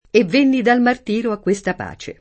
e vv%nni dal mart&ro a kkU%Sta pe] (Dante); I suoi piacer non son senza martire [